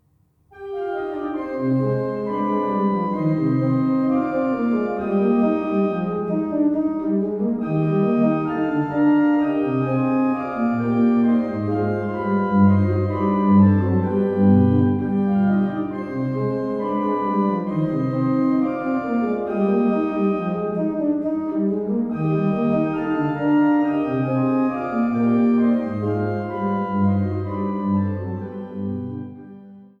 Glatter-Götz-/Rosales-Orgel im Remter des Magdeburger Domes